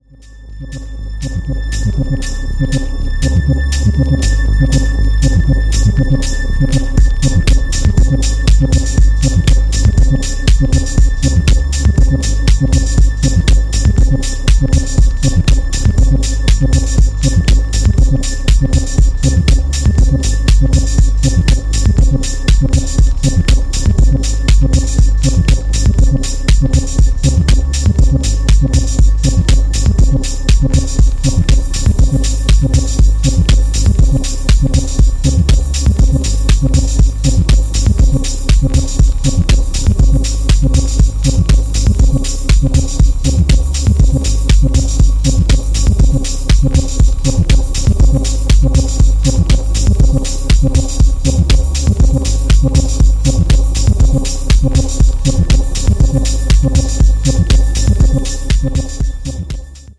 不気味に振動するサブベースが渋いテックファンク”A3”。